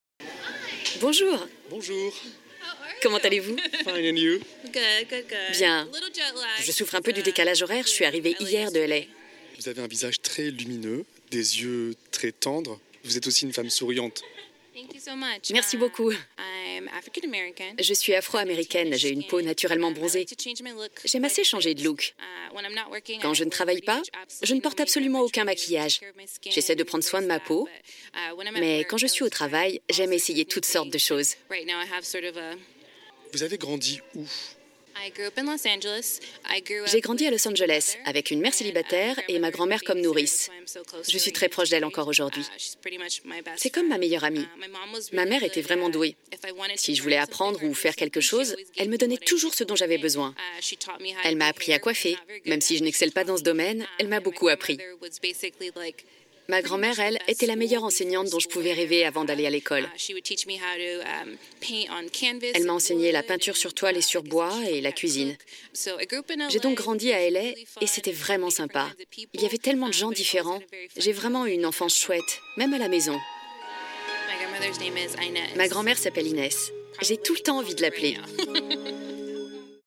voix douce voix naturelle voix témoignage